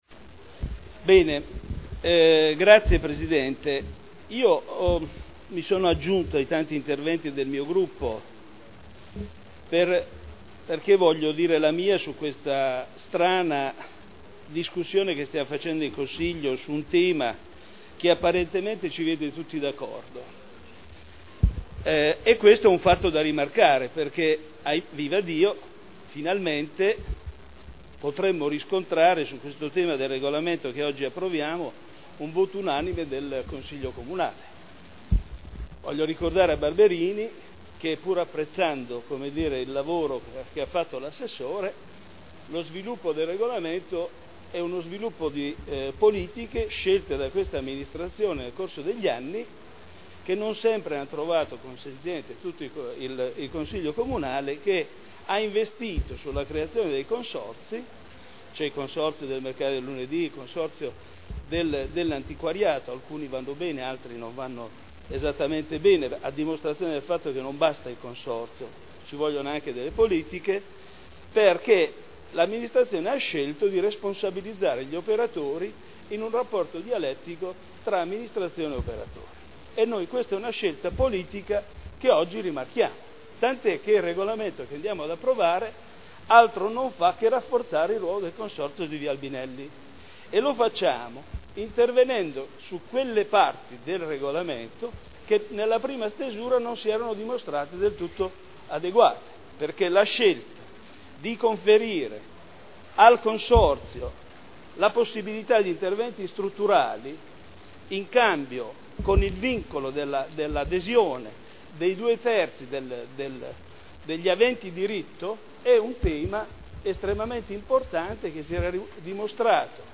Michele Andreana — Sito Audio Consiglio Comunale
Seduta del 17/01/2011. Regolamento comunale del mercato coperto quotidiano di generi alimentari denominato “Mercato Albinelli” – Approvazione modifiche Discussione